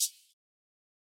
Closed Hats
Shameless Hi-Hat.wav